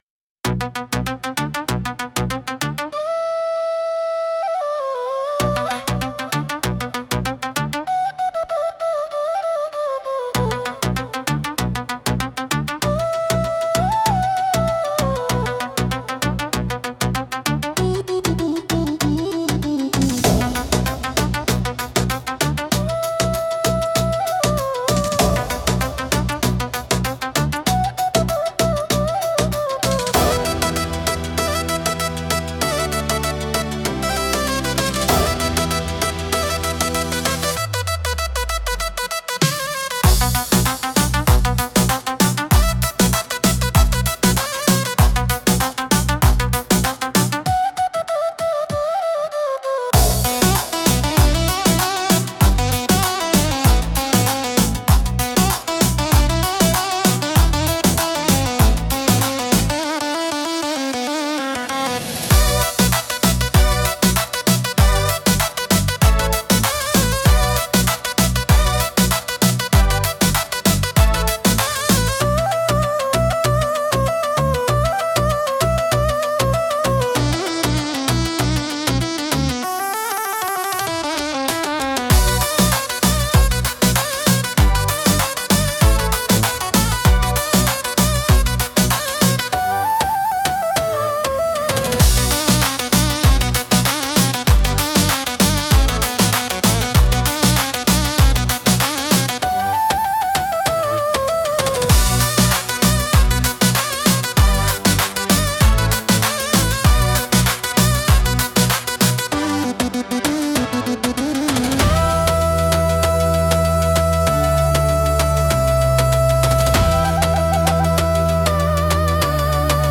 アラビア音楽は、中東地域に伝わる伝統的な音楽スタイルで、独特の旋律（マカーム）と複雑なリズムが特徴です。
ウードやカヌーン、ダラブッカなどの民族楽器が使われ、神秘的でエキゾチックな雰囲気を醸し出します。
独特のメロディとリズムで聴く人の感覚を刺激します。